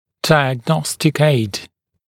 [ˌdaɪəg’nɔstɪk eɪd][ˌдайэг’ностик эйд]вспомогательное диагностическое средство (напр. рентгеновские снимки и т.п.)